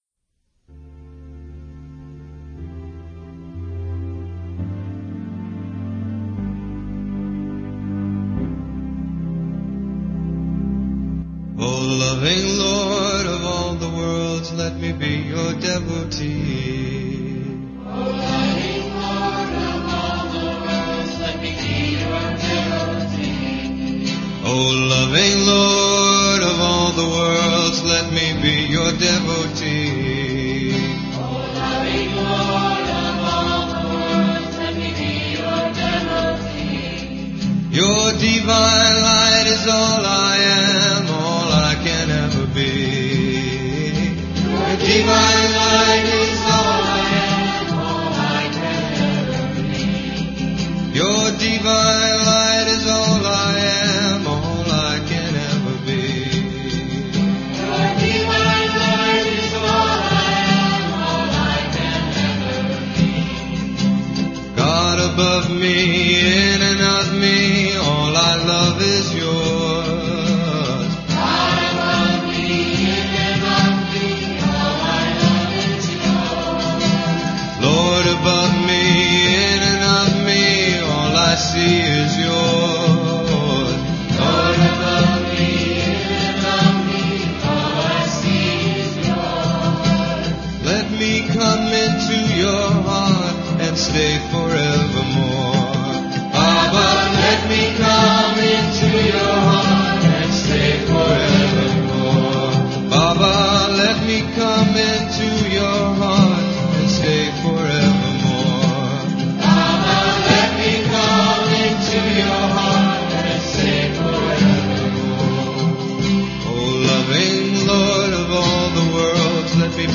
Major (Shankarabharanam / Bilawal)
8 Beat / Keherwa / Adi
4 Pancham / F
1 Pancham / C